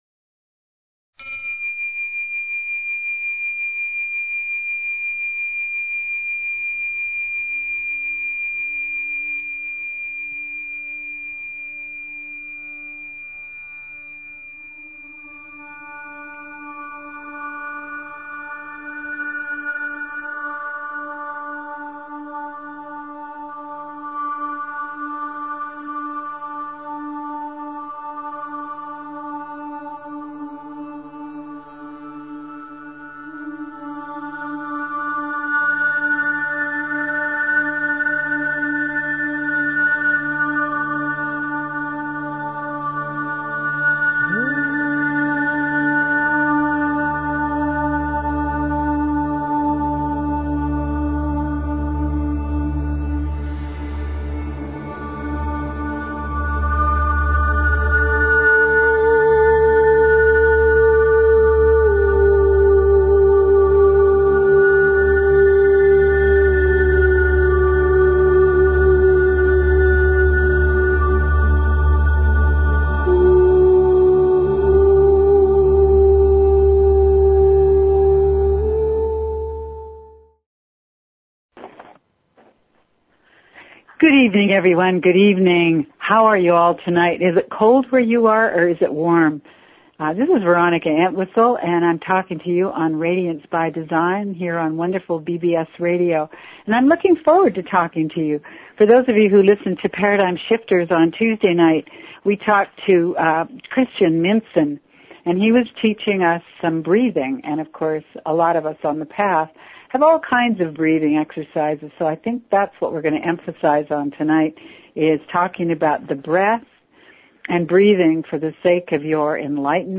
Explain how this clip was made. Radiance is a call in show so call in about your life, your questions, the trickery that you find in your daily routine.